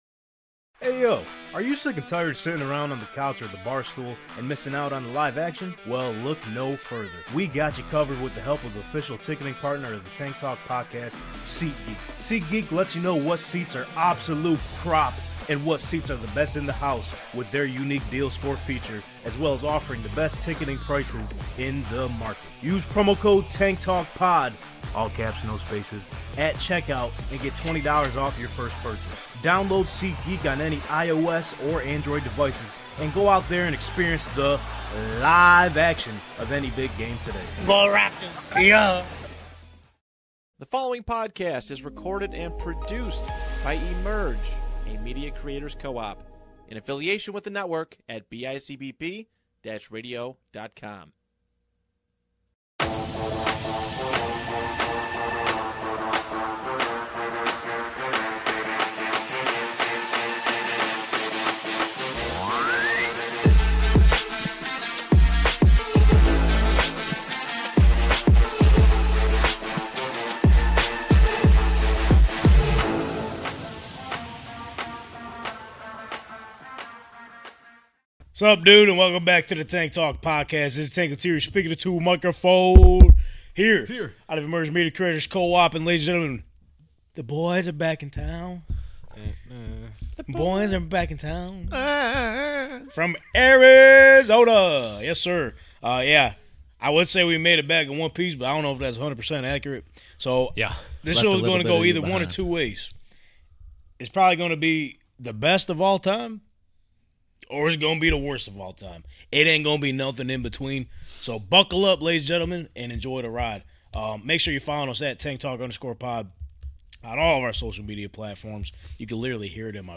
Join us LIVE every Monday at 6:30pm EST. on the Tank Talk Podcast YouTube channel, streamed out of Emerge: Media Creators Co-op based in Niagara Falls, NY.